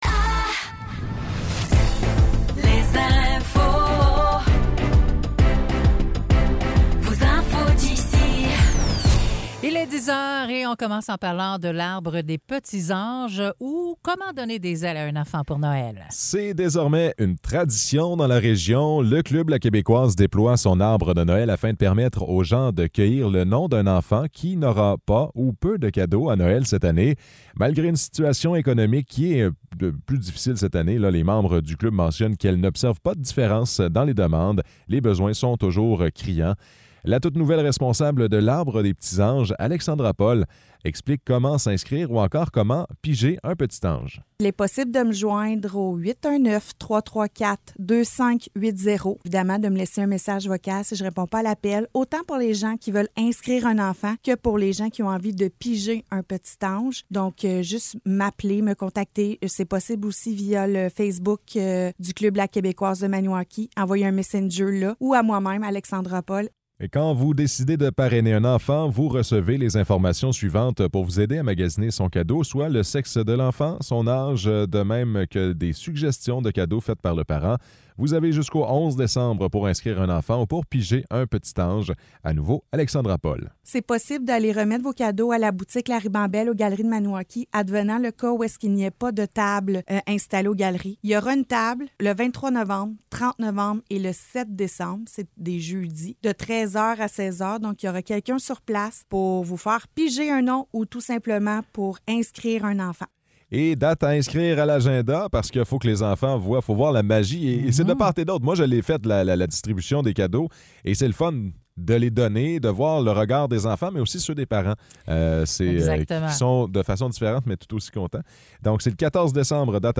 Nouvelles locales - 16 novembre 2023 - 10 h